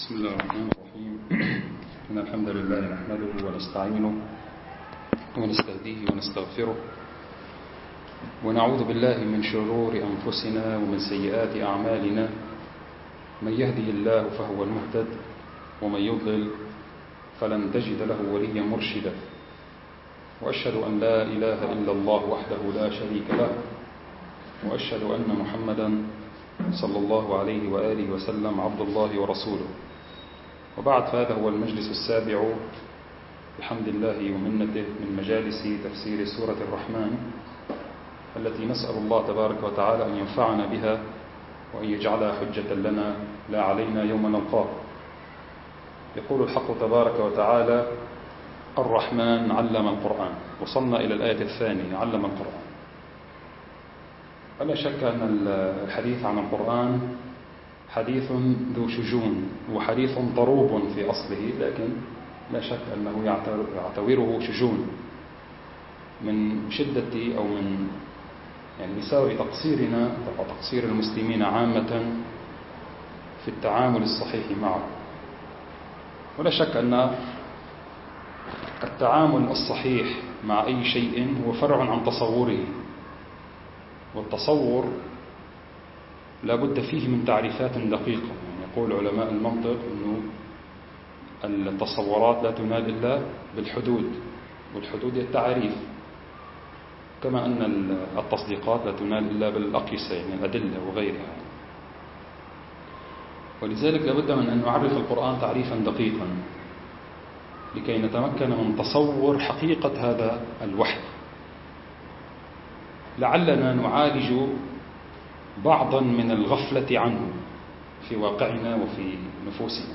المكان: مركز جماعة عباد الرحمن